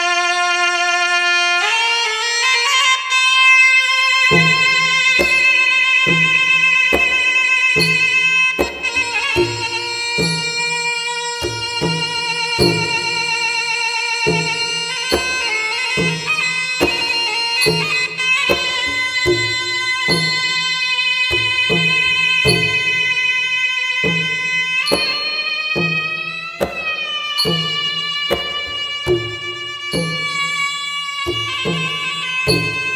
Tradition Khmer music played before Khmer boxing match
Khmermusicboxing.wav